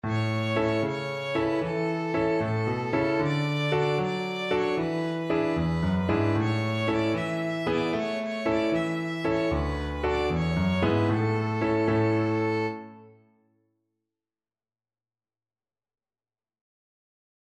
Violin
A major (Sounding Pitch) (View more A major Music for Violin )
6/8 (View more 6/8 Music)
Joyfully .=c.76
Traditional (View more Traditional Violin Music)